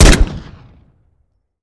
weap_decho_fire_plr_01.ogg